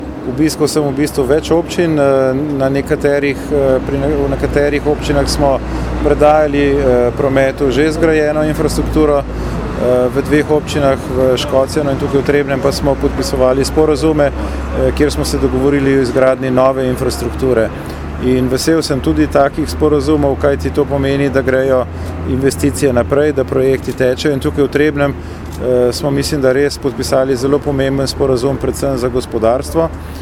izjava_dr_petra_gaspersica_ministra_za_infrastrukturo_29_9_2017.mp3 (524kB)